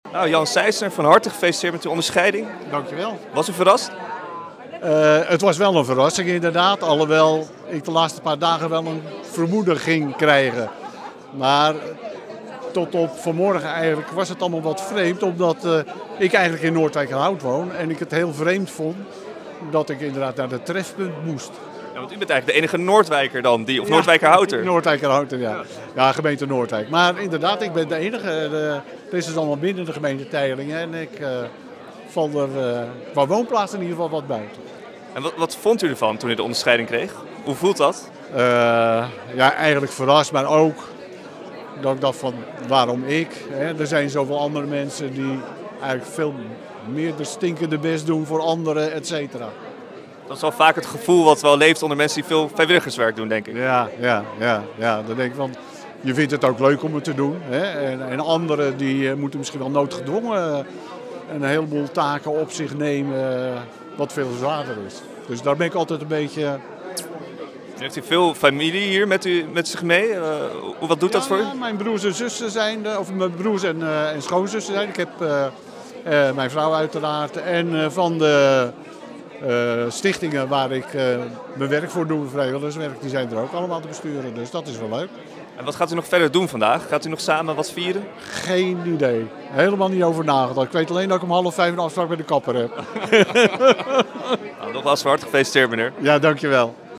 Het interview